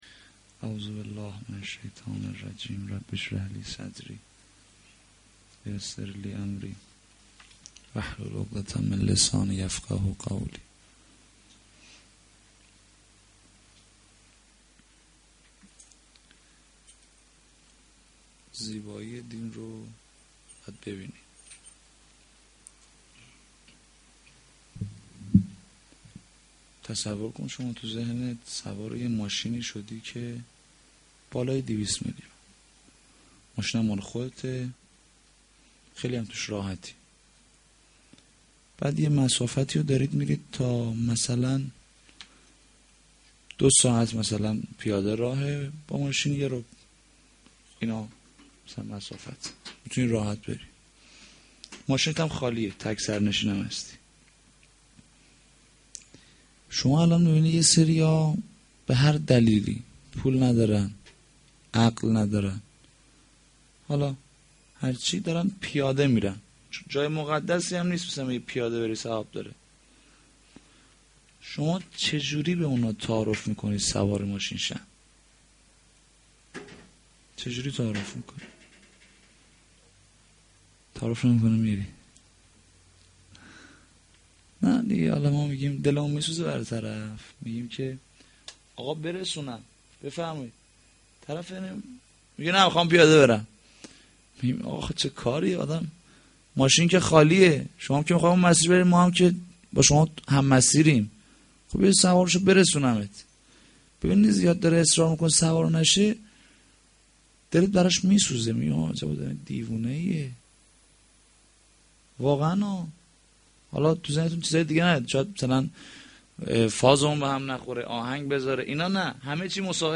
sh-3-moharram-92-sokhanrani.mp3